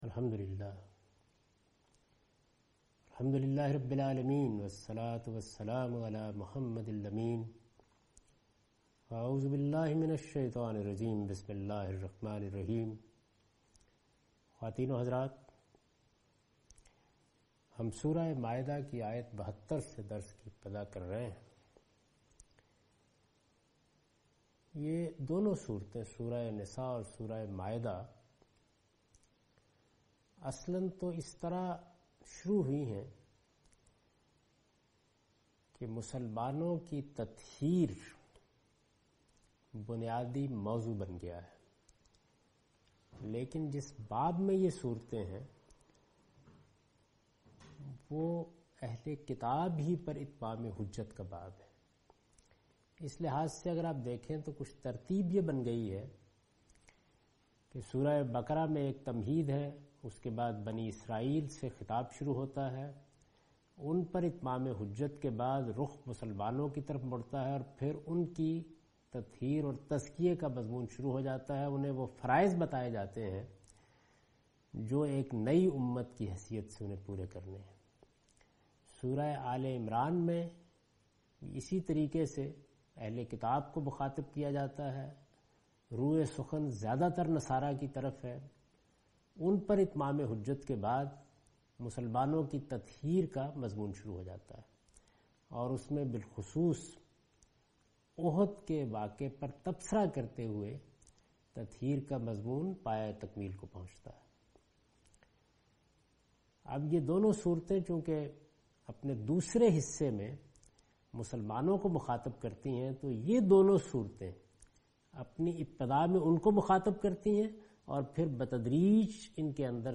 Surah Al-Maidah - A lecture of Tafseer-ul-Quran – Al-Bayan by Javed Ahmad Ghamidi. Commentary and explanation of verse 72 and 77